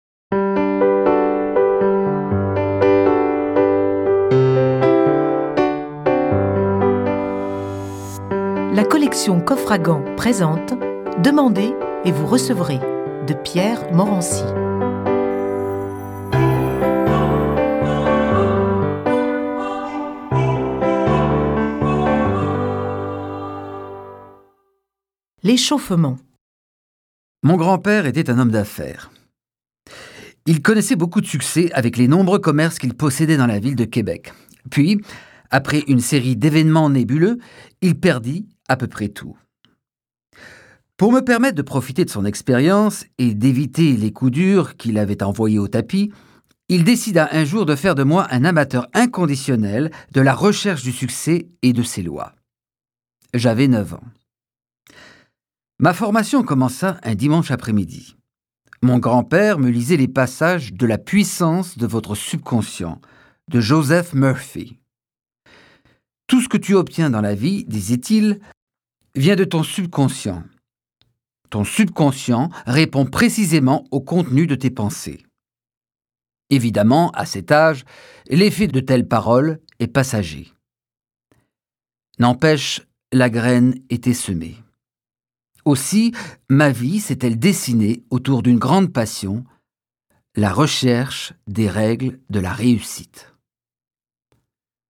Click for an excerpt - Demandez et vous recevrez de Pierre Morency